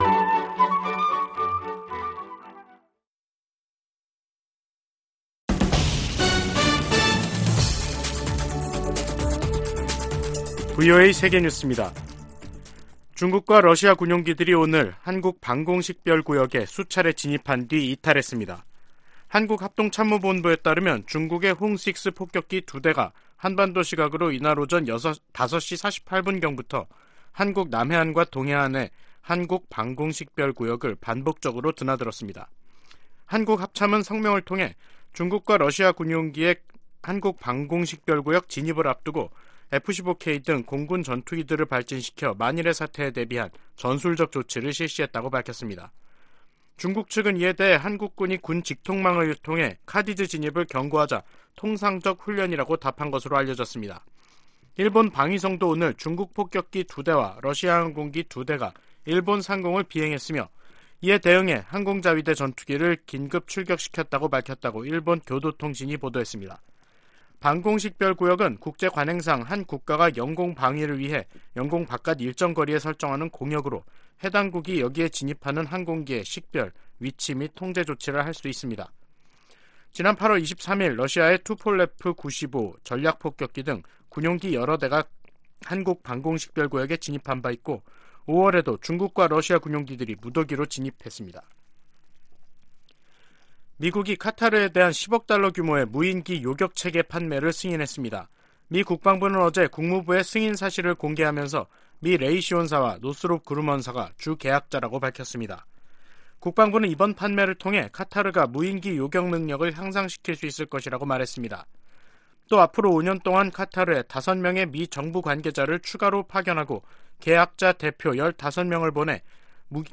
VOA 한국어 간판 뉴스 프로그램 '뉴스 투데이', 2022년 11월 30일 2부 방송입니다. 북한 정권이 7차 핵 실험을 강행하면 대가가 따를 것이라고 미국 국방부가 거듭 경고했습니다. 중국의 핵탄두 보유고가 2년여 만에 2배인 400개를 넘어섰으며 2035년에는 1천 500개에 이를 것이라고 미국 국방부가 밝혔습니다.